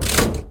lever1.ogg